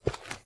STEPS Dirt, Walk 27, Donk.wav